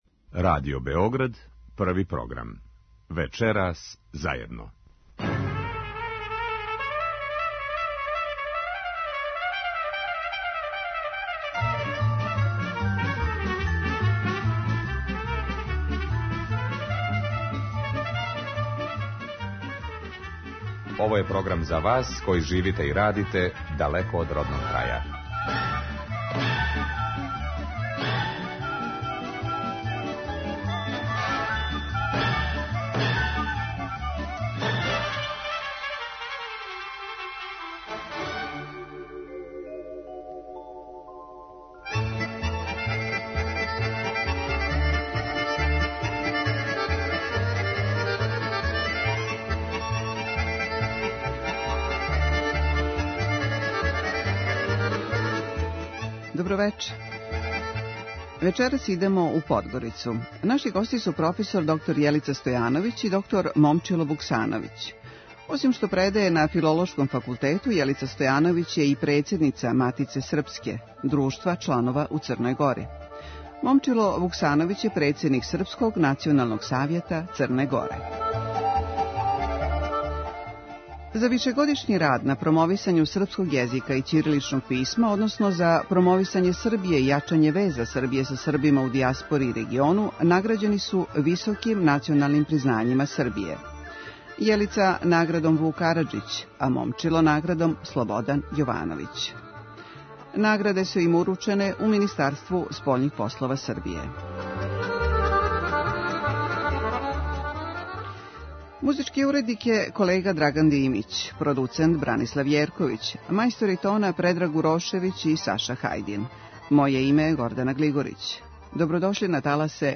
Емисија магазинског типа која се емитује сваког петка од 21 час.
Они су наши гости вечерас.